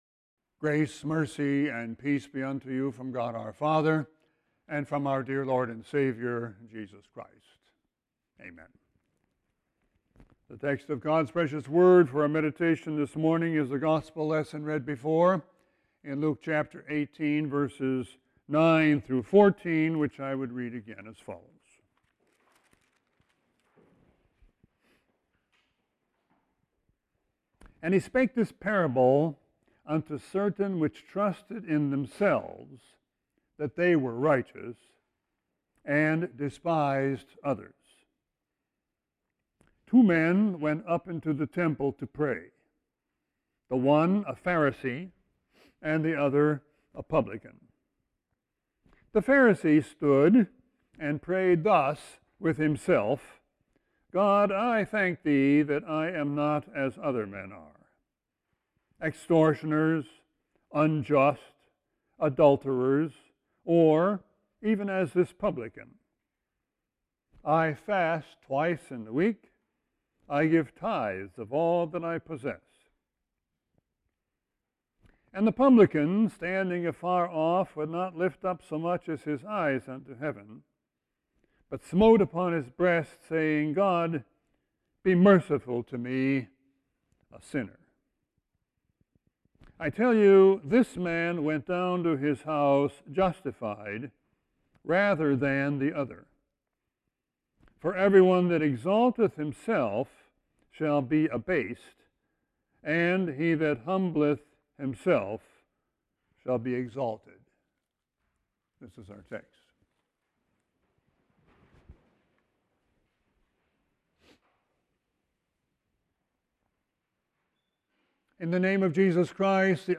Sermon 9-1-19.mp3